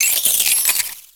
Cri de Trousselin dans Pokémon X et Y.